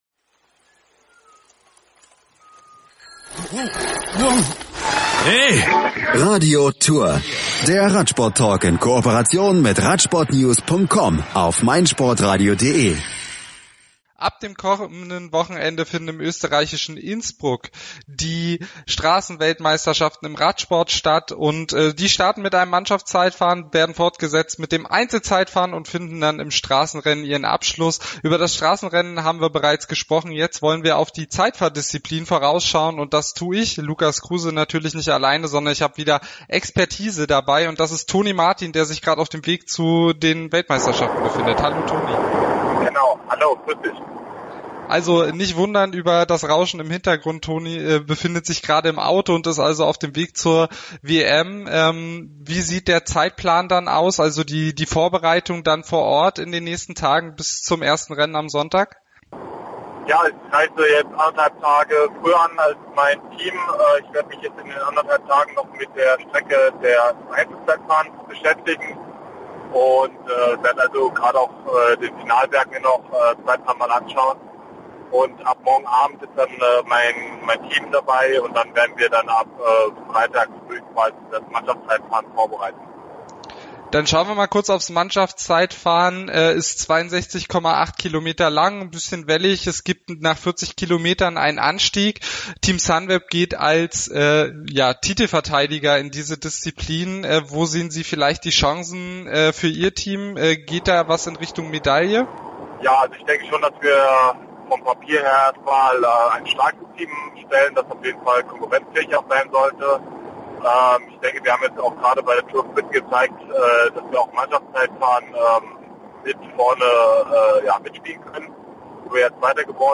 Im Interview berichtet er unter anderem von seiner Vorbereitung und seine Zielen. Zudem verrät er seine persönlichen Favoriten und schildert die Besonderheiten des Parcours in Innsbruck.